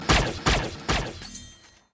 However if you listen to the one shot he was able to get off, you will here the classic Hollywood “fwip”.
watchmen-death-of-the-comedian-gun-silencer-sound.mp3